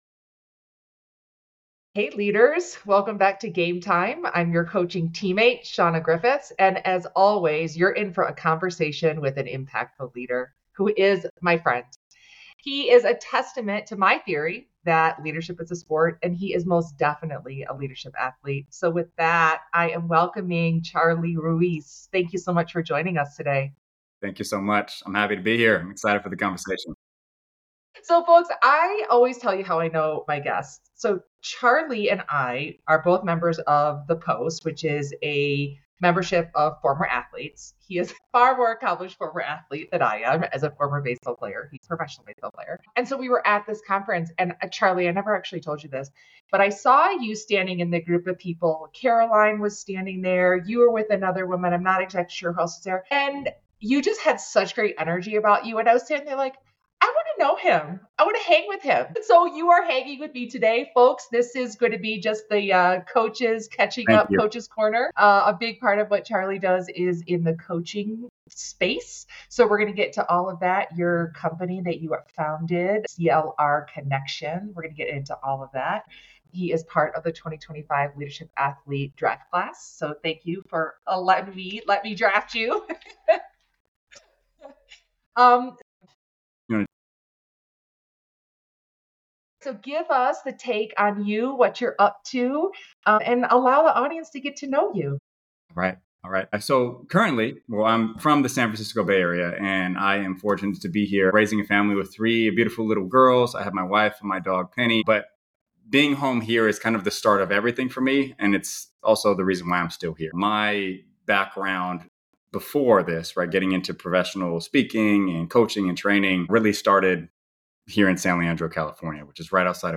In this episode you'll drop into the two of us chopping it up as Coaches, hitting on hot topics including: